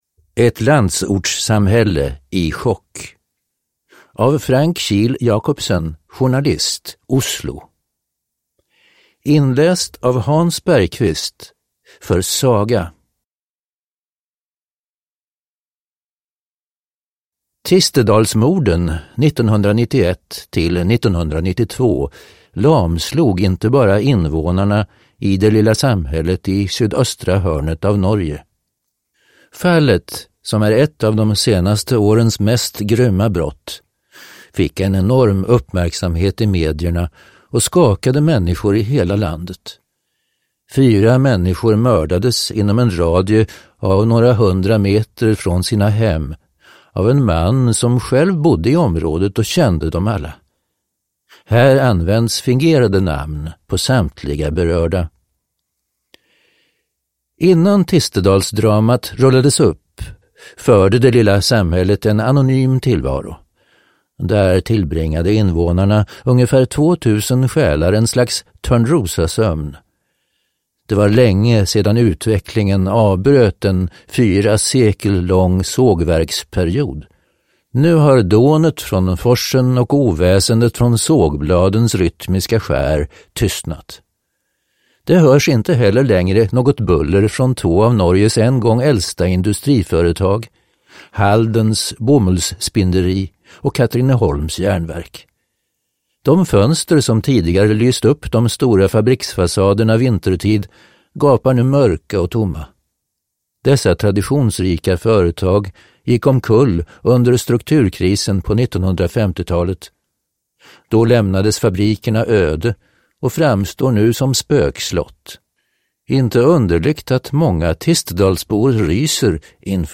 Ett landsortssamhälle i chock (ljudbok) av Diverse